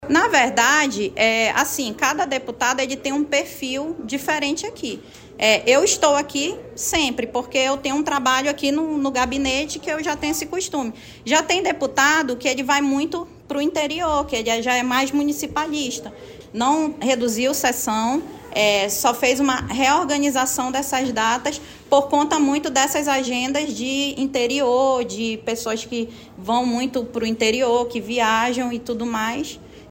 Na semana passada para a BandNews Difusora FM, uma das autoras da matéria, a deputada estadual Joana Darc (UB) defendeu a mudança e rebateu as críticas de que a alteração, teria como foco as eleições de 2026: